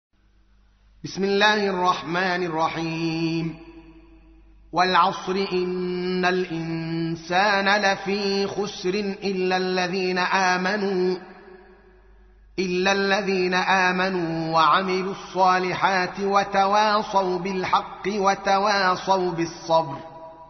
تحميل : 103. سورة العصر / القارئ الدوكالي محمد العالم / القرآن الكريم / موقع يا حسين